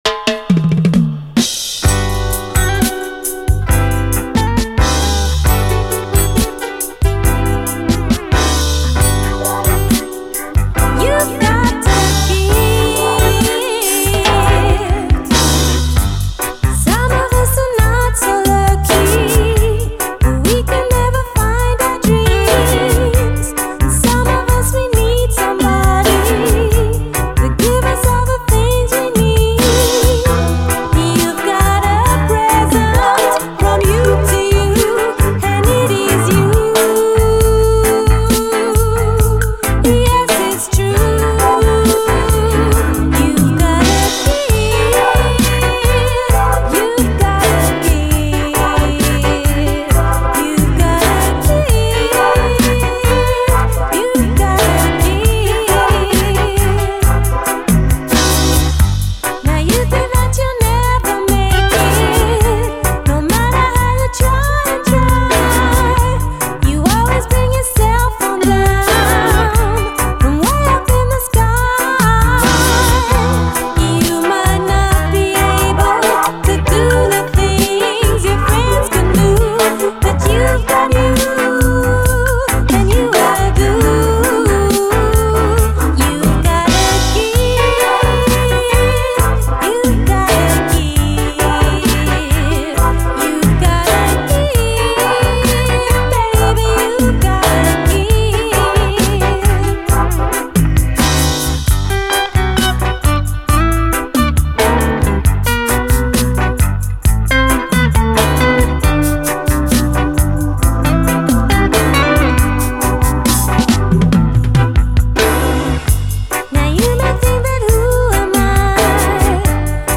REGGAE
盤スレ結構あり見た目はVG+ですが実際はノイズほぼなくプレイ良好。
試聴ファイルはこの盤からの録音です/　何ということ！